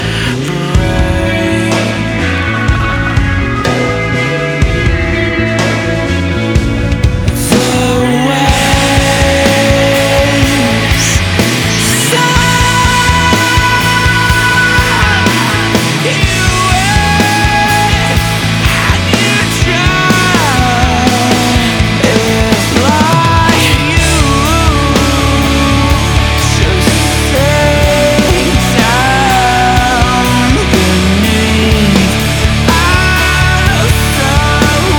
Hard Rock Rock Adult Alternative Metal Alternative
Жанр: Рок / Альтернатива / Метал